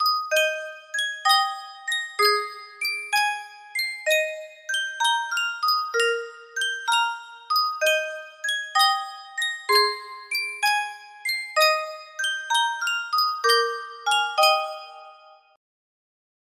Sankyo Music Box - Sweet Hour of Prayer TIT music box melody
Full range 60